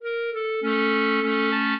clarinet
minuet10-5.wav